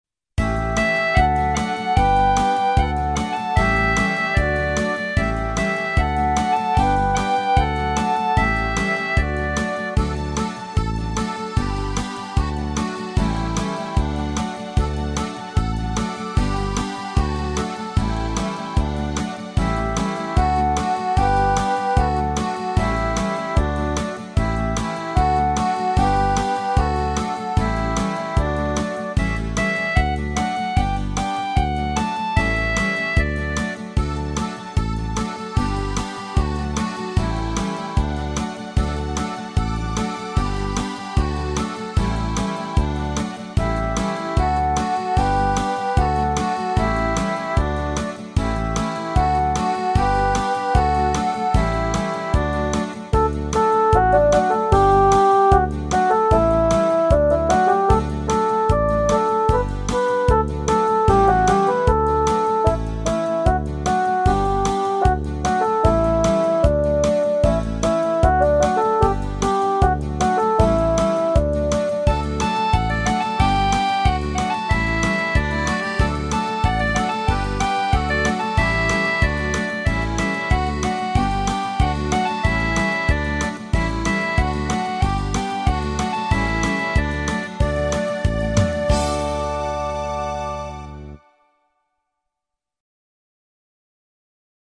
Музыка: Украинская народная